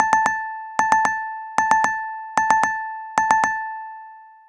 Index of /phonetones/unzipped/BlackBerry/OS-10/notification-tones/timers
notification_timer.m4a